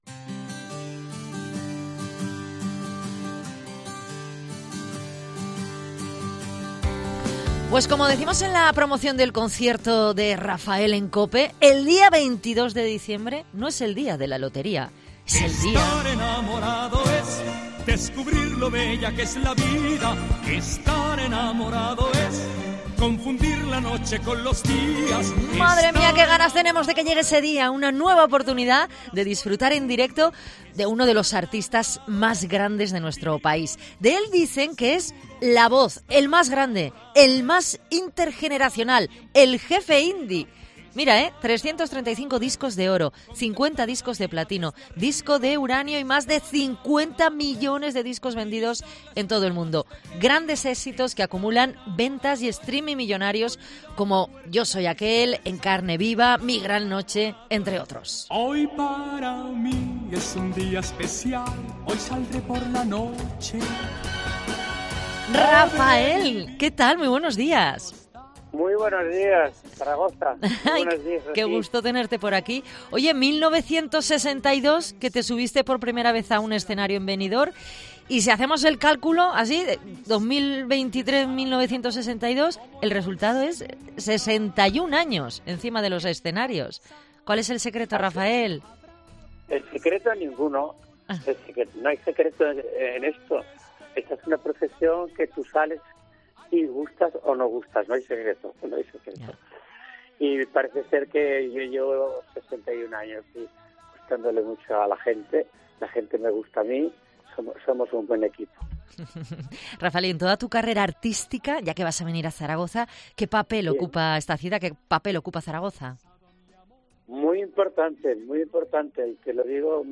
AUDIO: Entrevista al cantante Raphael con motivo del concierto que ofrece en el Pabellón Príncipe Felipe